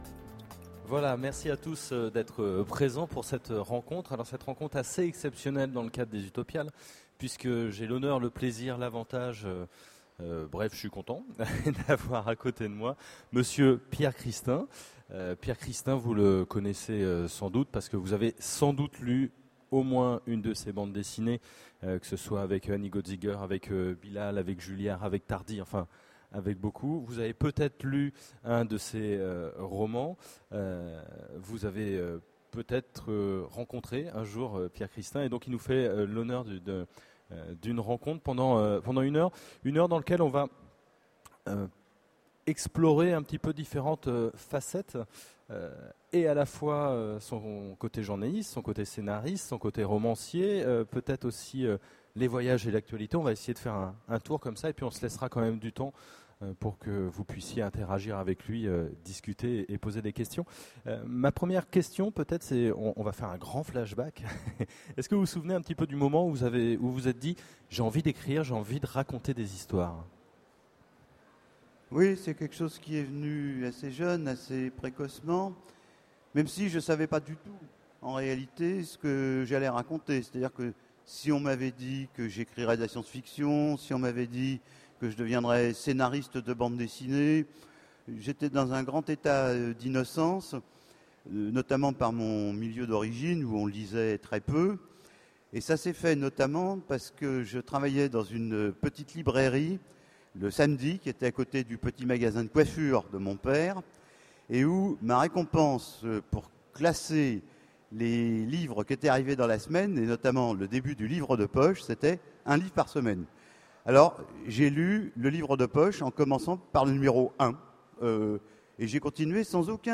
En 2009, il avait été l'invité des Utopiales. Vous pouvez réécouter son grand entretien en cliquant ici.